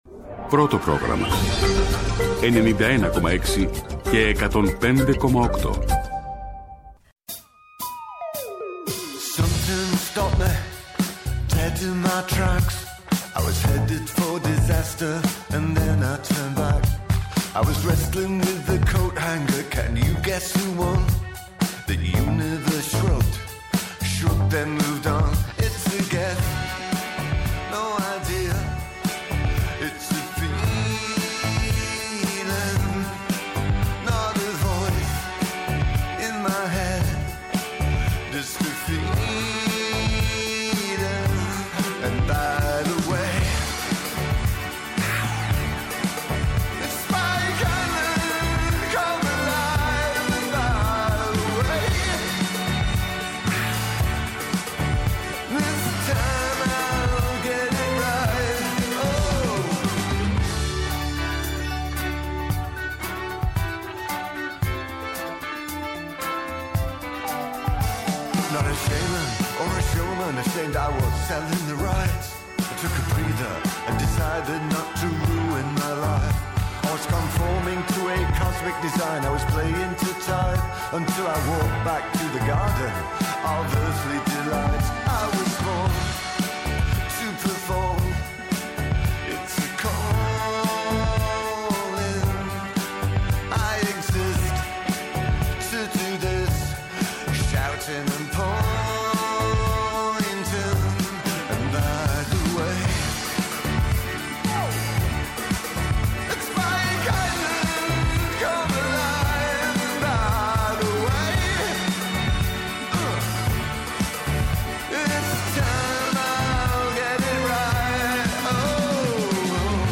επικαιρότητα με νέες ηχογραφήσεις
ΜΟΥΣΙΚΗ